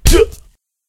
hit2.ogg